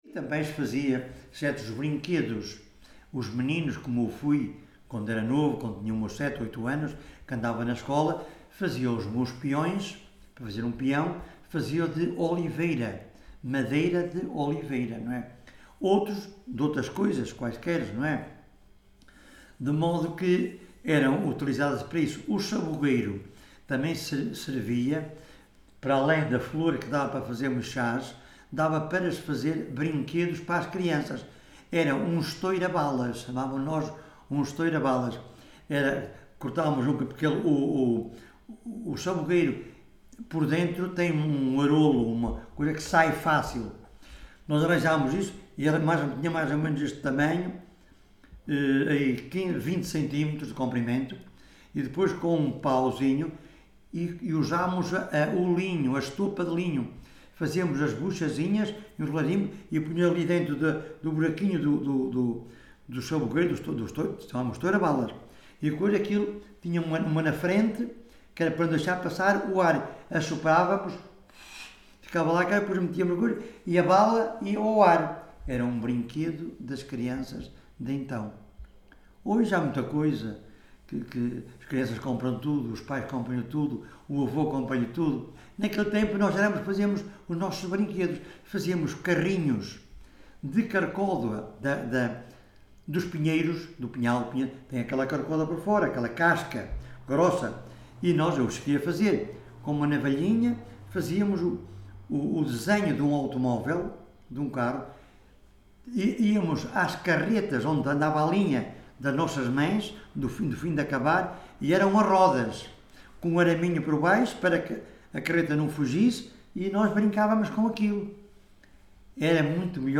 Várzea de Calde, primavera de 2019.
Tipo de Prática: Inquérito Oral